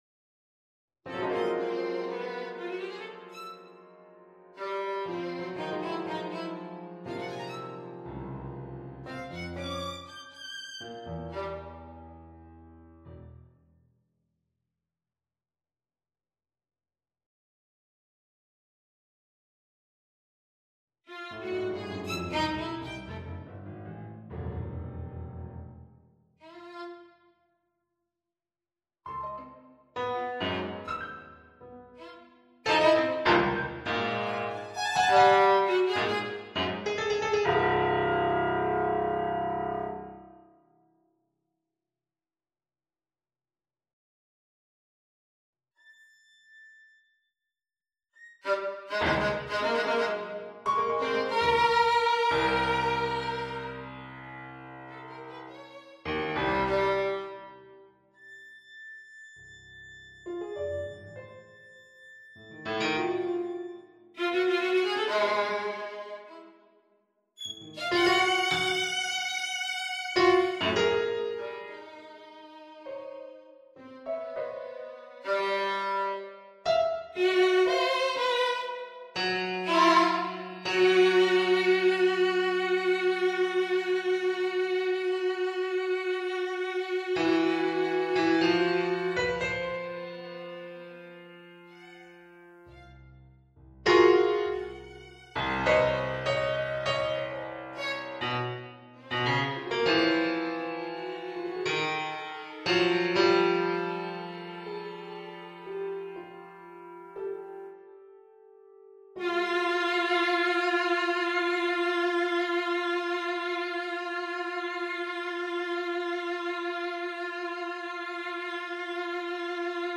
(Noteperformer-Version)
Das Stück (Tempo: 60 bpm) beginnt mit einem sich über eine Oktave erstreckenden, arpeggierten Cluster im Klavier, während sich die Violine, ebenfalls im Fortissimo, einer Tritonusbewegung nach oben bedient und im Laufe der nächsten Takte zwei motivische Hauptelemente der Komposition vorstellt.
Das Klavier hingegen spielt tendenziell in tiefen Lagen und bedient sich, von zwei Ausbrüchen abgesehen, der leisen Töne.
Im achten Takt kommt es zu einem raschen Tempowechsel auf 100 bpm, der Fokus wird auf die isolierte Behandlung des 16tel-Elementes (I) sowie den Wechsel zwischen „pizzicato“ und „arco“ in der Geigenstimme gesetzt.
Das rhythmisch-motivische Konzept wird untermauert durch eine kontrastreiche Behandlung der Dynamiken; diese bewegen sich im Bereich von dreifachem Piano bis zu dreifachem Forte.
stueck_fuer_klavier_und_violine.mp3